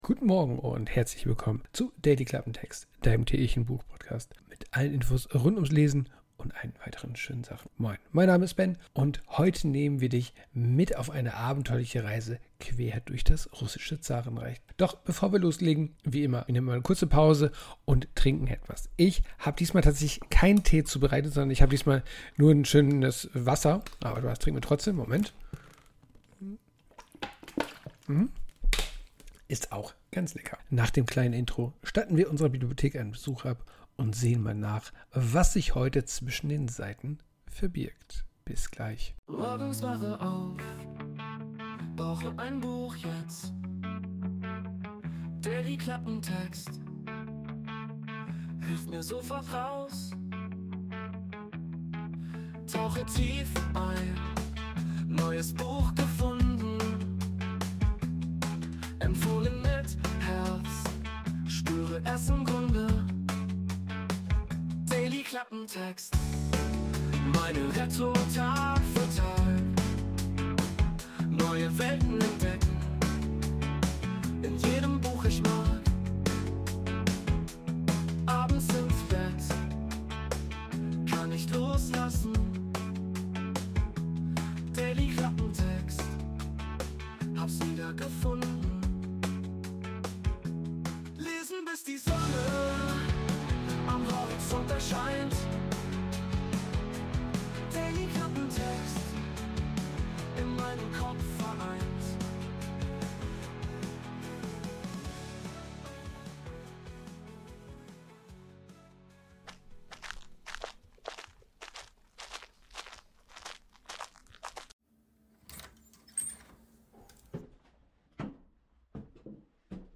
Orchestral Sweeping Dramatic Music
Intromusik: Wurde mit der KI Suno erstellt.